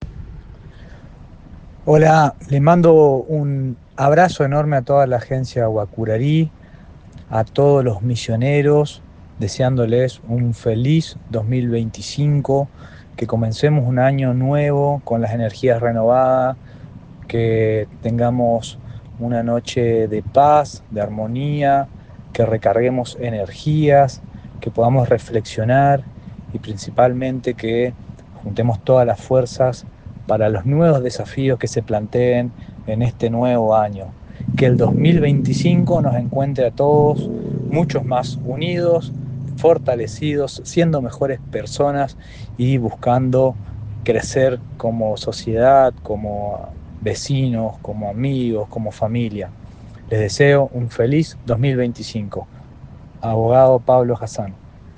El Intendente de Oberá, Pablo Hassan, envió un cordial saludo de fin de año a todo el pueblo misionero.